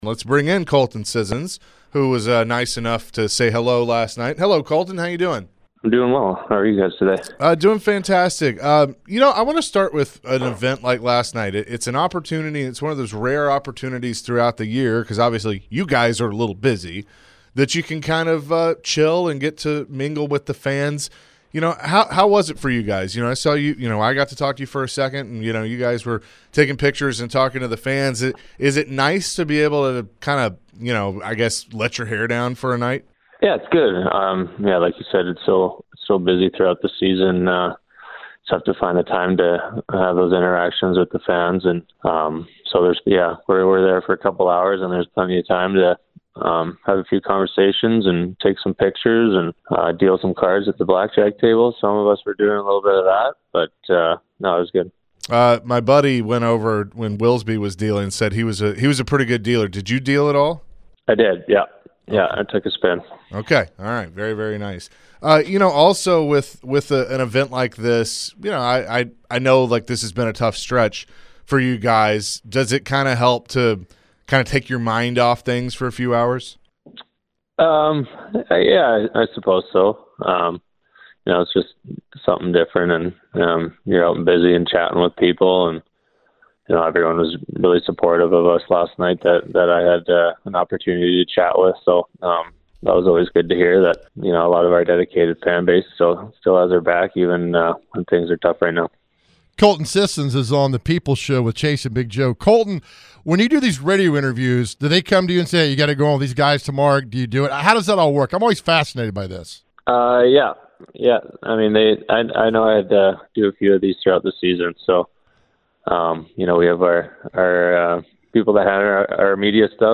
The guys open up the show chatting with Nashville Predators forward Colton Sissons. Colton shared his thoughts on how the Preds have been playing recently. Later in the conversation, the guys poked some fun with Colton and if he enjoys doing interviews.